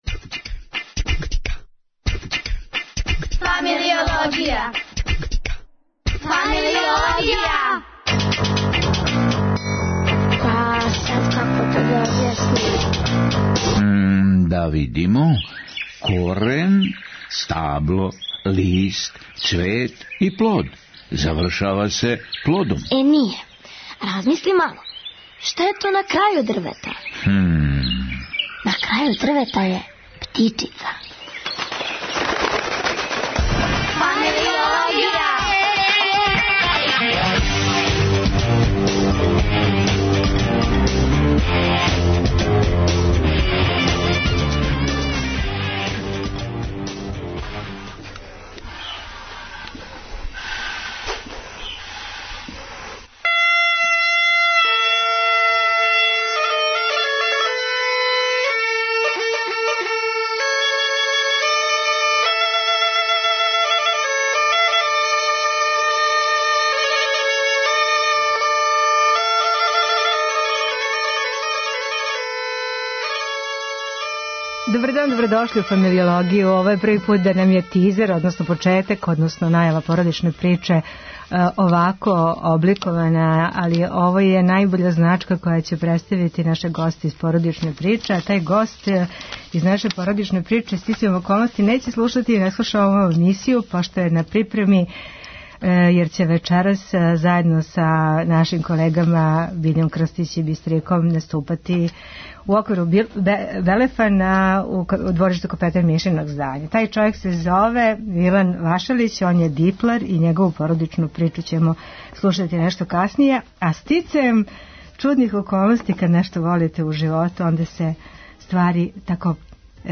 породични терапеут.